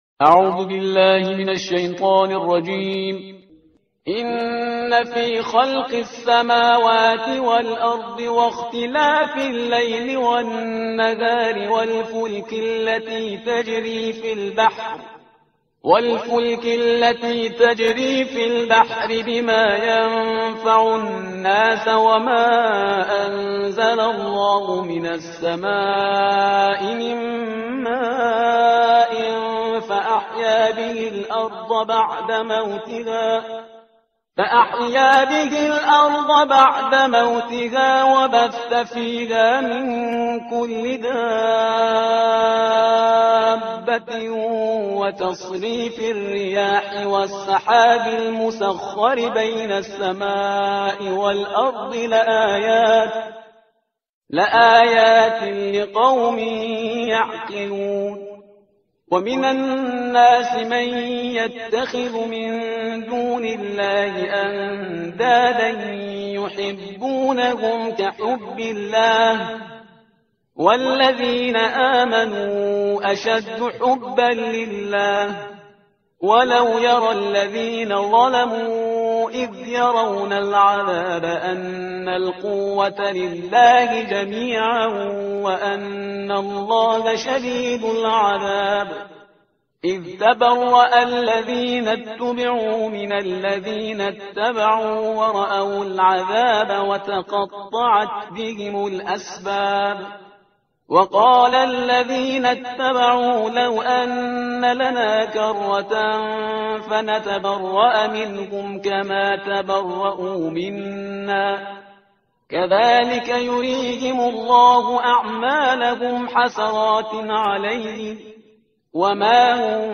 ترتیل صفحه 25 قرآن با صدای شهریار پرهیزگار